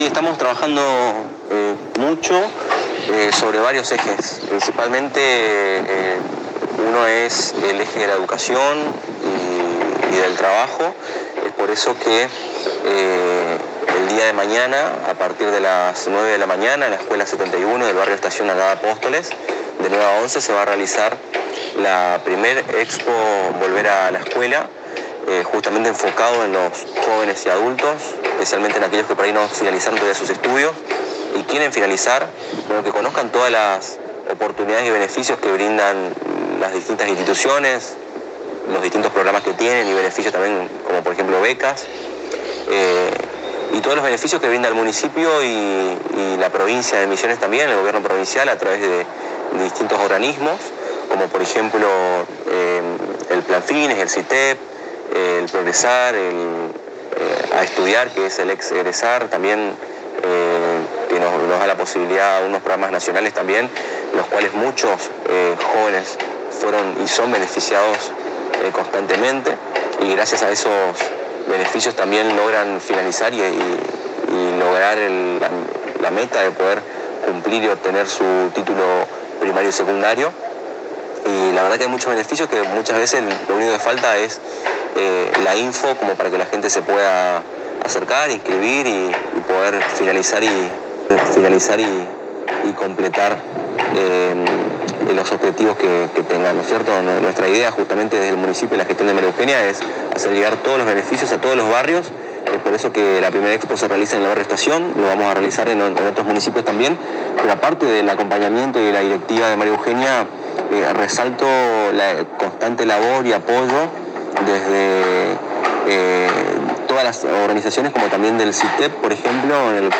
En diálogo exclusivo con la ANG, el Director de la Juventud de Apóstoles, Aldo Muñoz, señaló que desde el área a su cargo están trabajando mucho sobre varios ejes, especialmente sobre la educación y el trabajo.